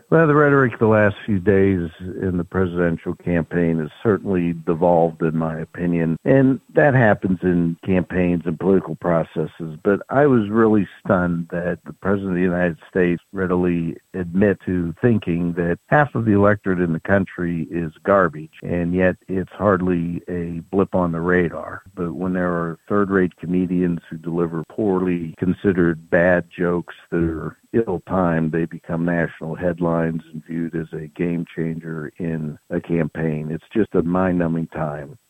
Pennsylvania State Senator Joe Pittman gave a reaction to the Biden comment to Renda Media news on Wednesday.